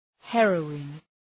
Shkrimi fonetik {‘herəʋın}